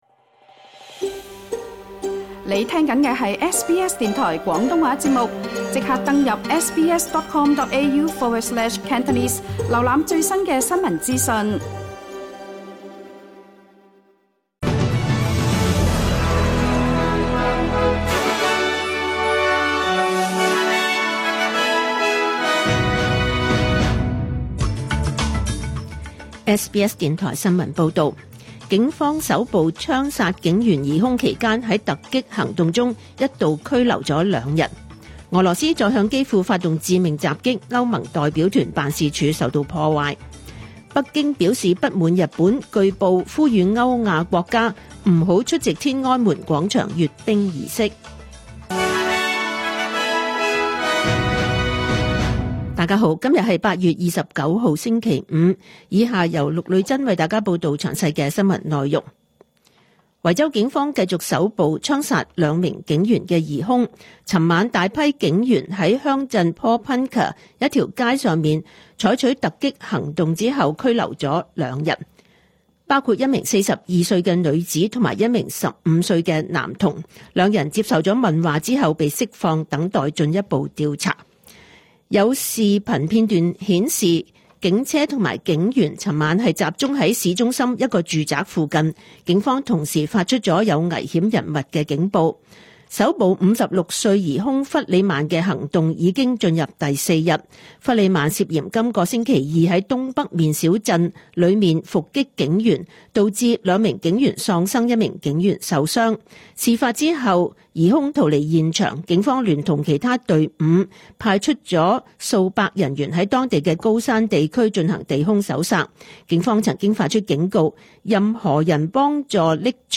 2025 年 8 月 29 日 SBS 廣東話節目詳盡早晨新聞報道。